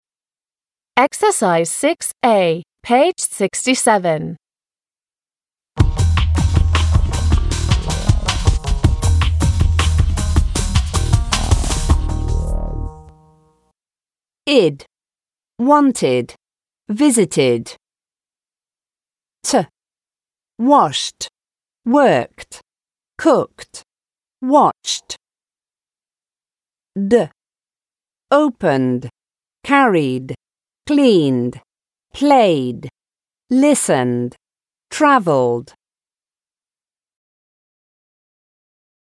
• /id/: wanted, visited;
• /t/: washed, worked, cooked, watched;
• /d/: opened, carried, cleaned, played, listened, travelled.